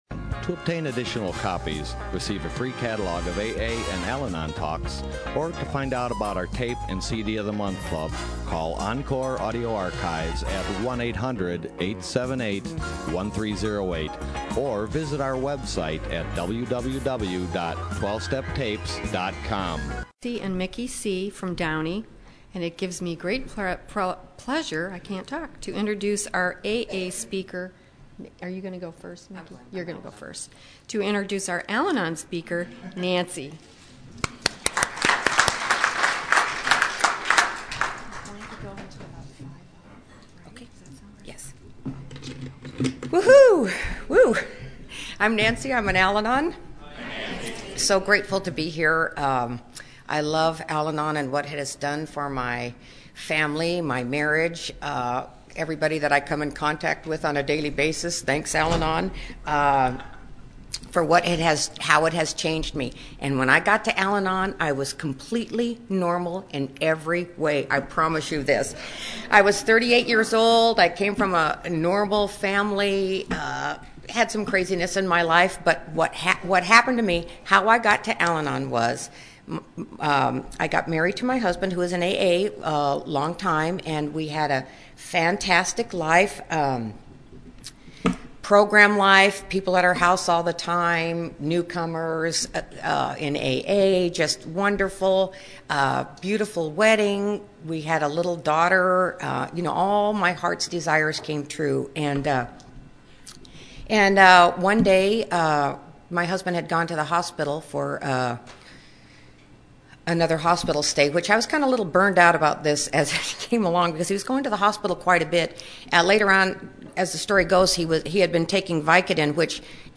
Encore Audio Archives - 12 Step Recovery Orange County AA Convention 2015